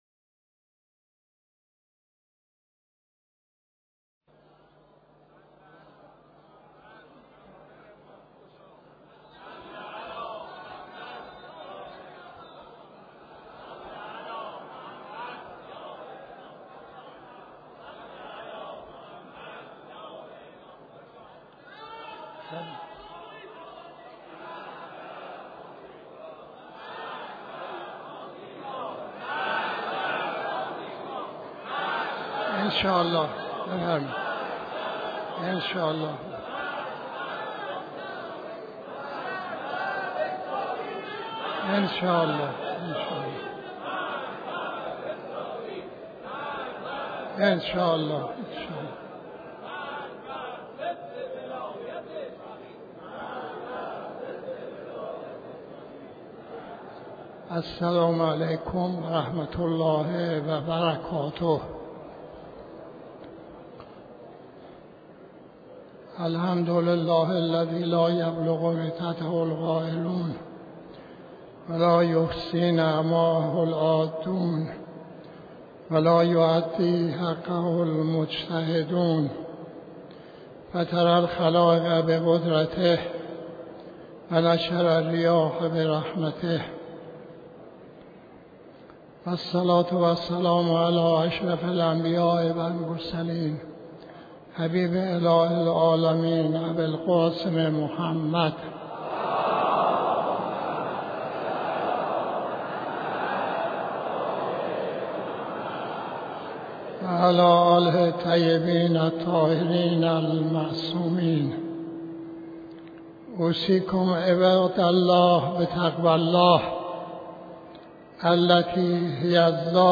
خطبه نماز جمعه 27-05-91